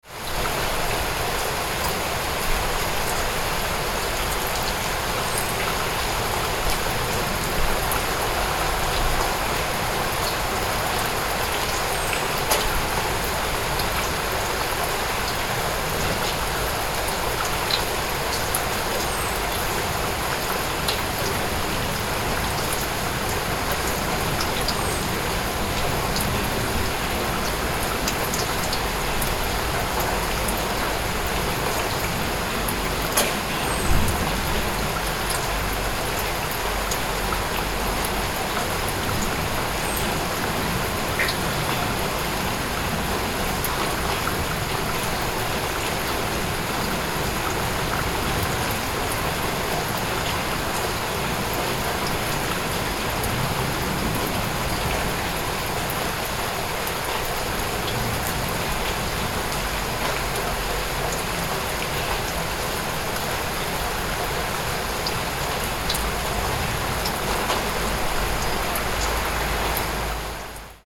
Real-rainfall-sound-effect.mp3